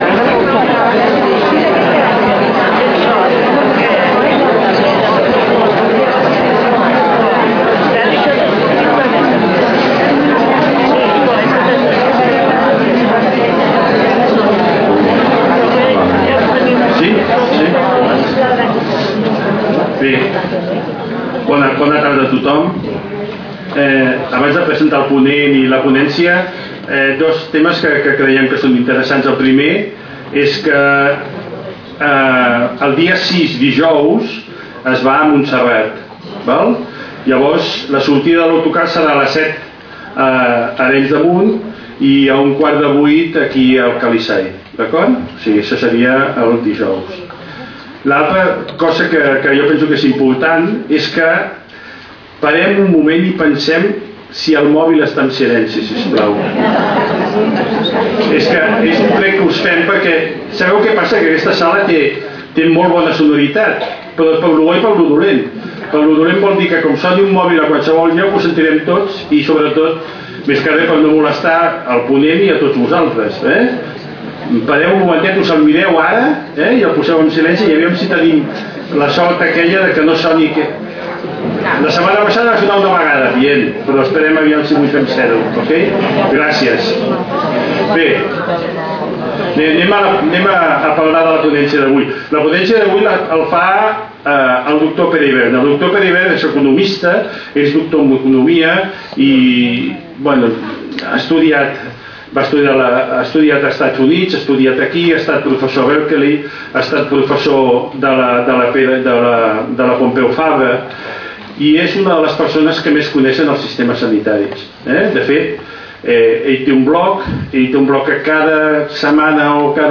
Lloc: Casal de Joventut Seràfica
Conferències